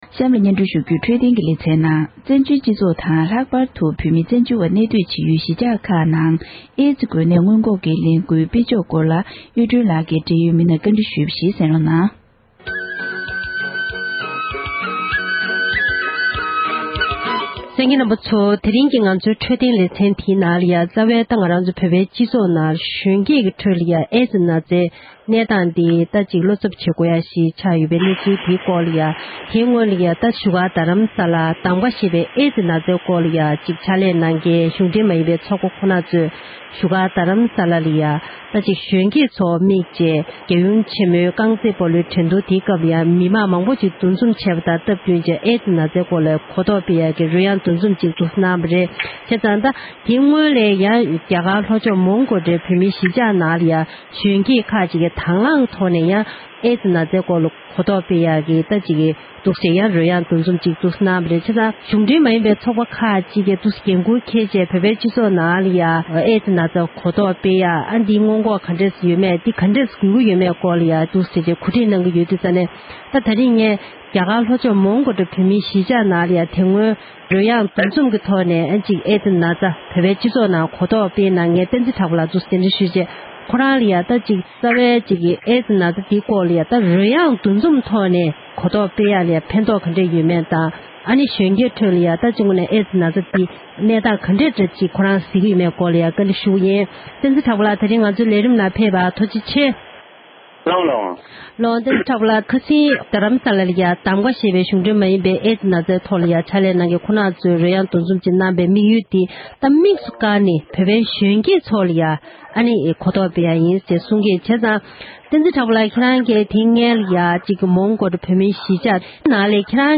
འབྲེལ་ཡོད་མི་སྣ་ཞིག་ལ་བཀའ་འདྲི་ཞུས་པར་གསན་རོགས